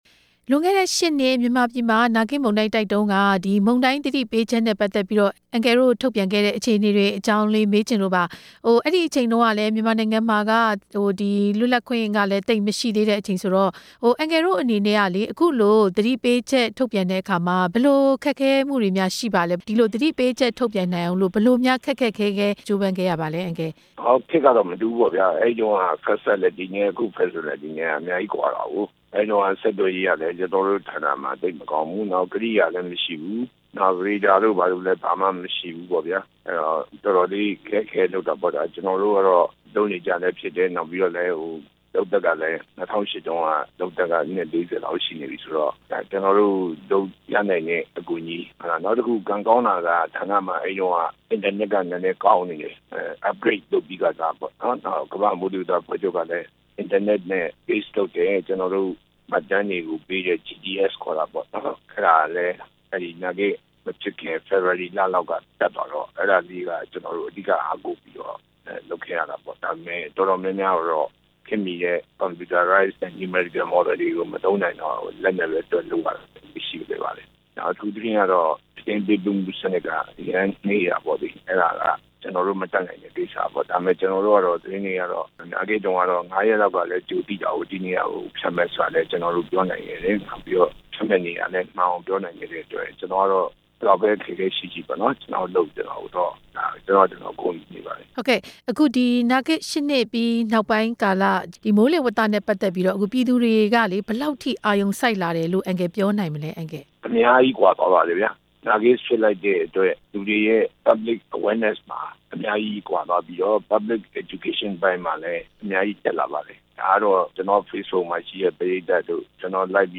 နာဂစ်လေမုန်တိုင်း ၈ နှစ်ပြည့်၊ မိုးလေဝသပညာရှင် ဦးထွန်းလွင် နဲ့ မေးမြန်းချက်